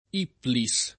Ipplis [ & ppli S ]